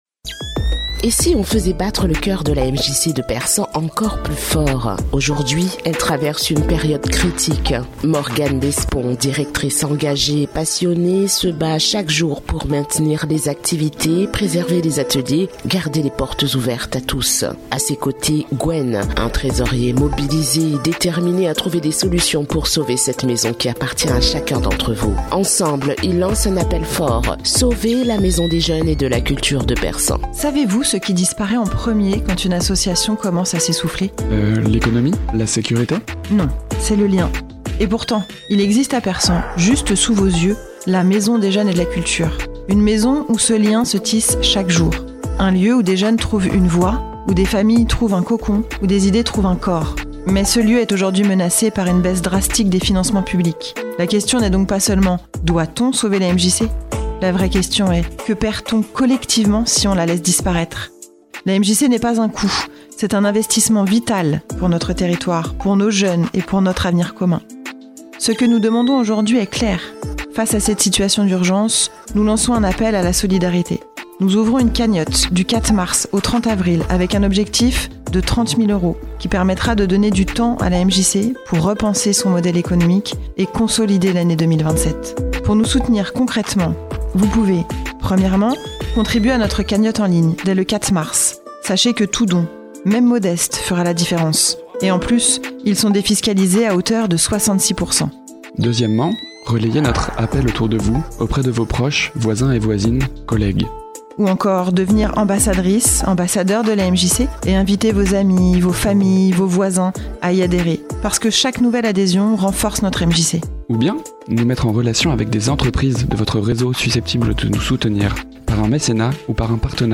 TEEZER-DEUX-VOIX-SAUVONS-LA-MJC-DE-PERSAN.mp3